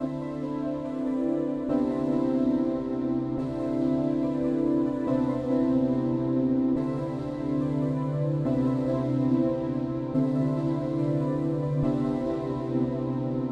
60年代早期的英国流行音乐鼓乐
标签： 142 bpm Pop Loops Drum Loops 2.28 MB wav Key : Unknown